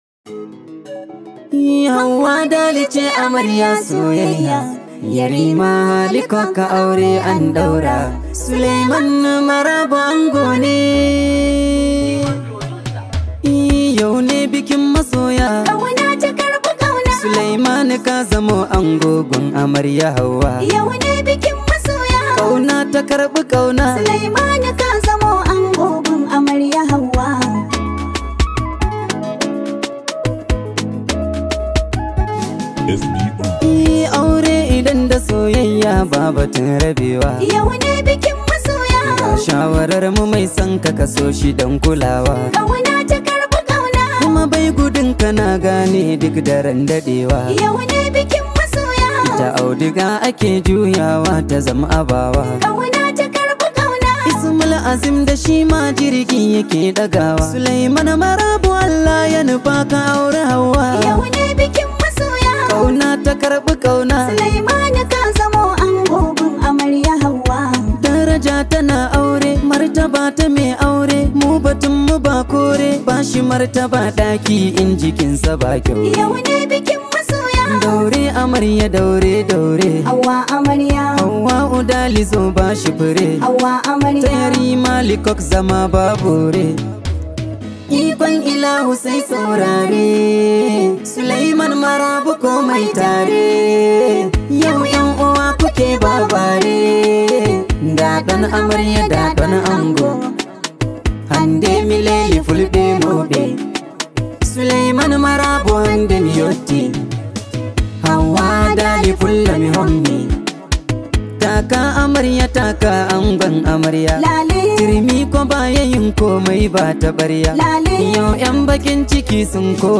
Hausa Songs
This high vibe hausa song